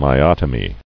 [my·ot·o·my]